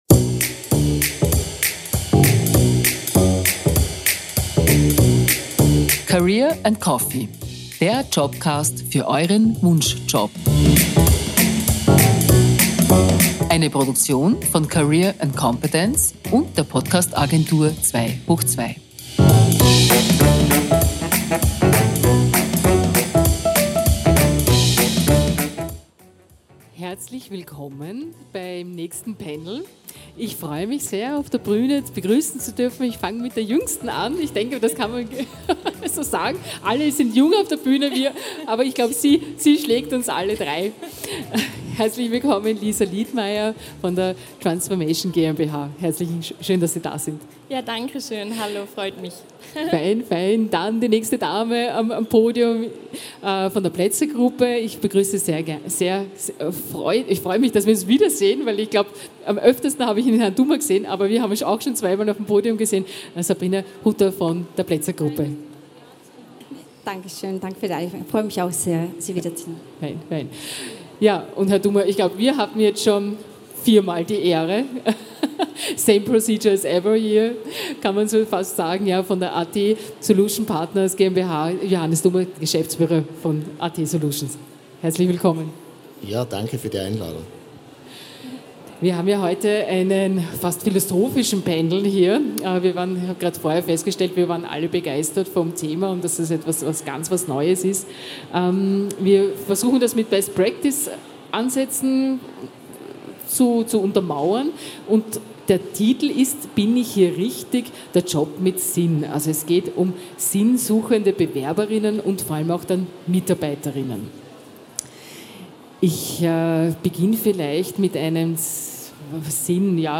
Livemitschnitt von der career & competence 2023 in Innsbruck, am 26. April 2023.